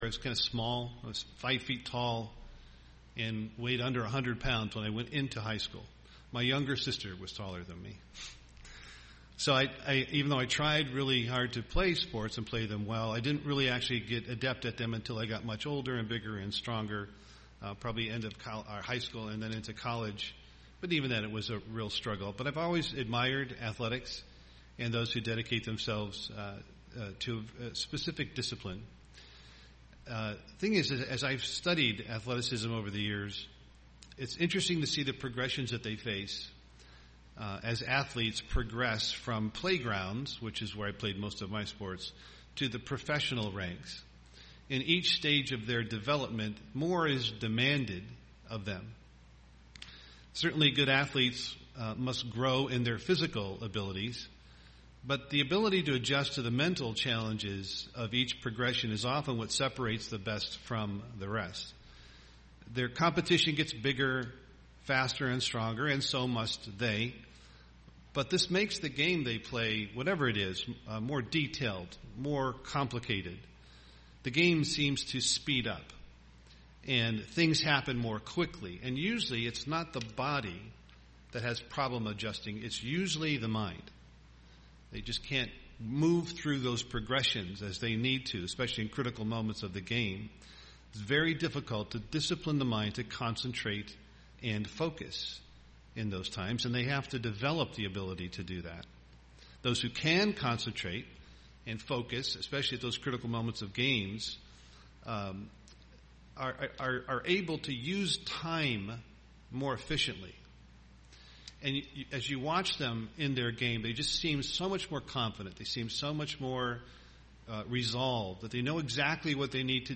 UCG Sermon Christian life Studying the bible?